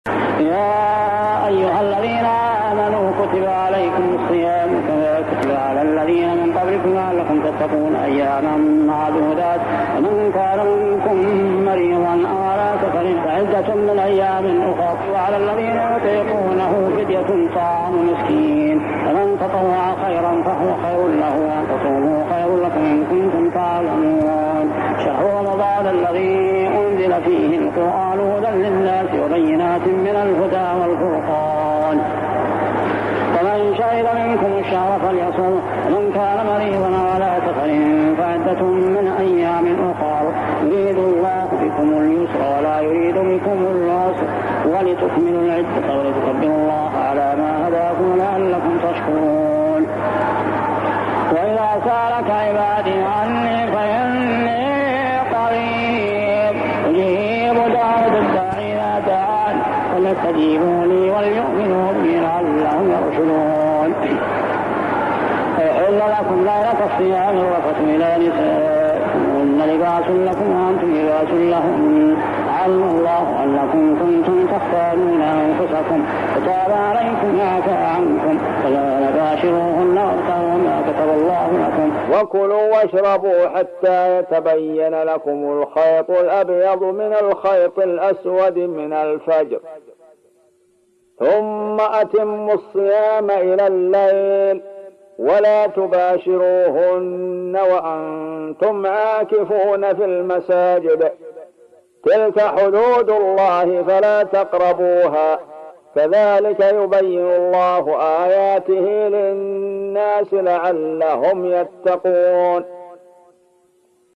صلاة التهجد عام 1398هـ من سورة البقرة 183-187 | Tahajjed prayer surah Al-Baqarah > تراويح الحرم المكي عام 1398 🕋 > التراويح - تلاوات الحرمين